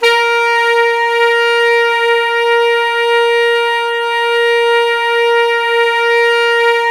SAX_sma#4x    22.wav